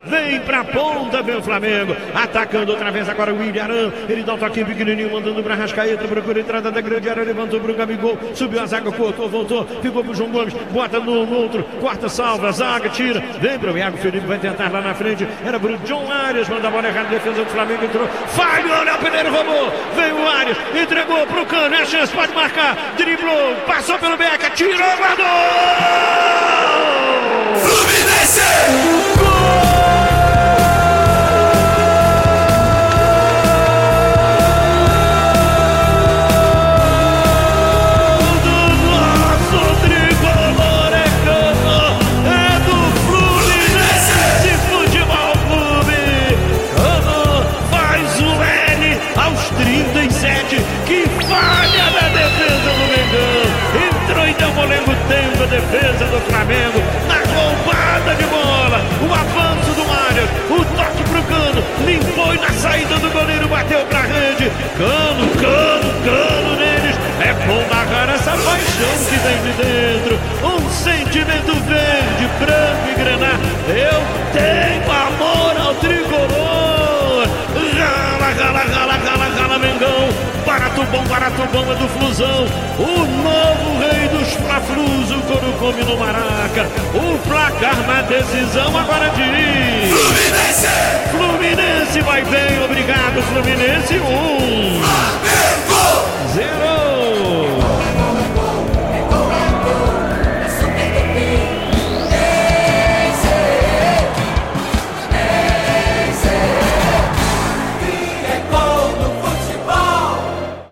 Ouça os gols da vitória do Fluminense sobre o Flamengo pela final do Carioca com a narração de Luiz Penido